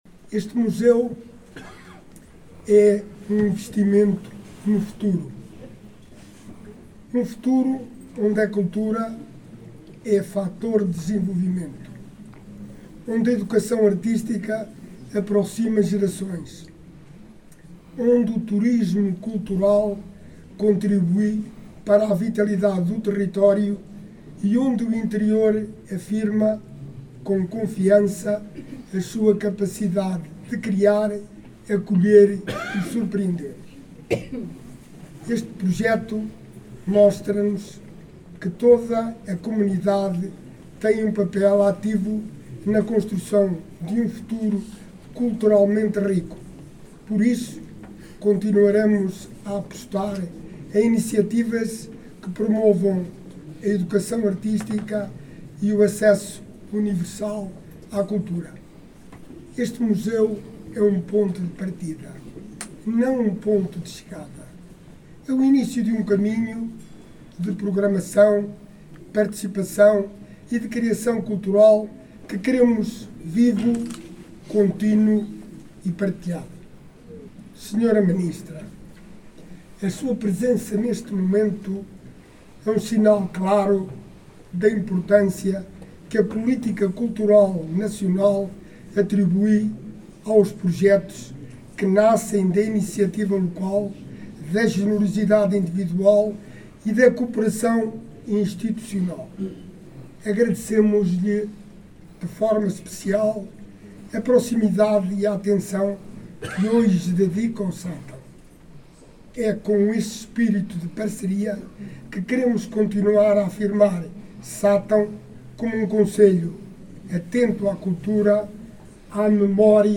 Nesta segunda-feira, 19 de janeiro, a localidade de Pedrosas, no concelho de Sátão, foi palco da inauguração do Museu de Pintura Naïf, numa cerimónia que contou com a presença da Ministra da Cultura, Juventude e Desporto, Margarida Balseiro Lopes, e de D. António Luciano, Bispo de Viseu.
Durante o seu discurso, Alexandre Vaz, Presidente do Município de Sátão, mostrou-se satisfeito com a concretização desta obra, destacando o impacto positivo que o novo museu terá na comunidade.